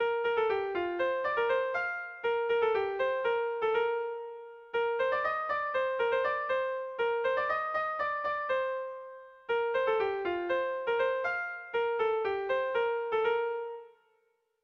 Bertso melodies - View details   To know more about this section
Seiko handia (hg) / Hiru puntuko handia (ip)
ABA